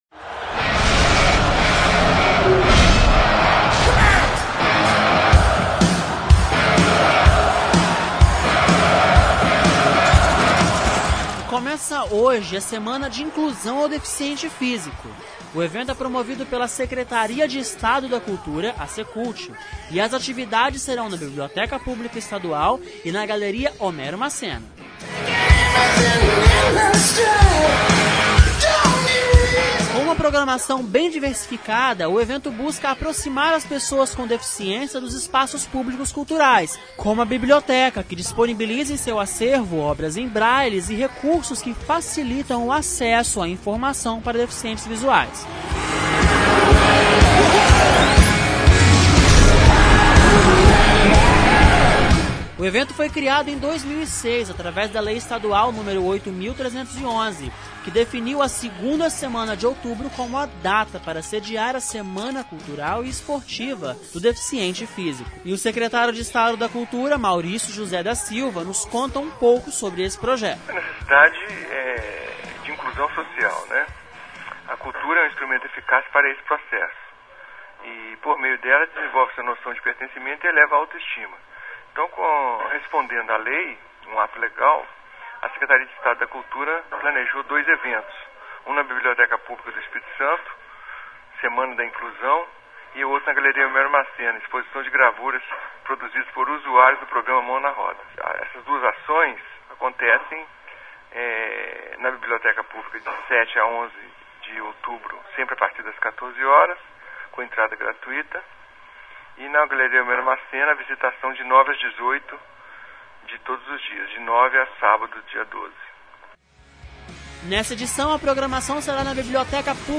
Aproveitamos a oportunidade e conversamos com o Secretário de Estado da Educação, Maurício da Silva, para nos contar quais são as novidades dessa edição.
Também falamos com o Subsecretario de Pessoas com Deficiência, Aníbal Chieza Filho, para comentar sobre a importância de implementar políticas de acessibilidade.